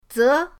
ze2.mp3